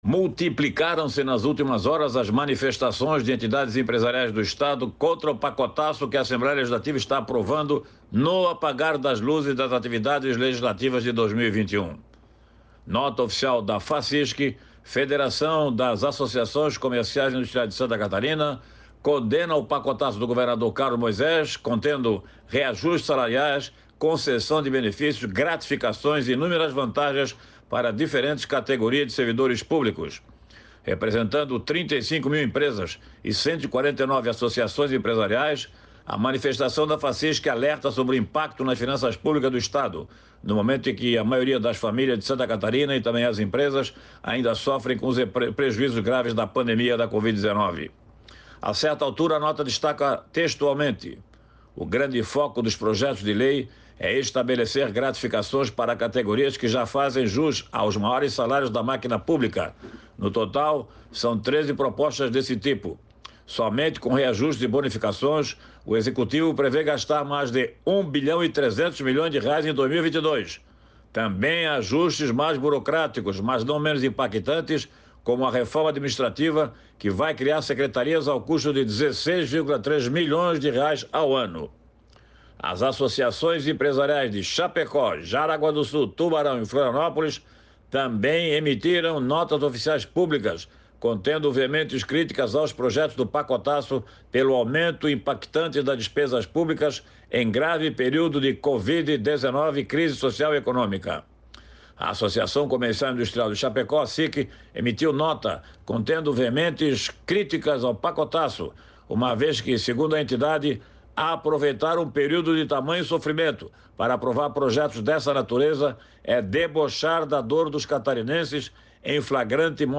Jornalista aborda reunião das três principais comissões técnicas da Alesc (Assembleia Legislativa do Estado de Santa Catarina) nesta segunda (20)